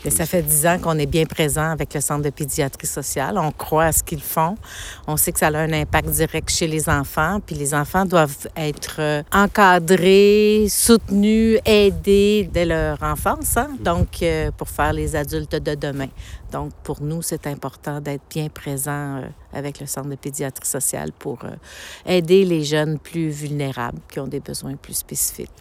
La mairesse a également réaffirmé, en conférence de presse ce matin, l’engagement de la Ville envers la Jeunesse.
CVille-CPSC-Main-dans-la-main_Sylvie-Beauregard-clip.mp3